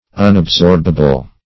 Search Result for " unabsorbable" : The Collaborative International Dictionary of English v.0.48: Unabsorbable \Un`ab*sorb"a*ble\, a. Not absorbable; specifically (Physiol.), not capable of absorption; unable to pass by osmosis into the circulating blood; as, the unabsorbable portion of food.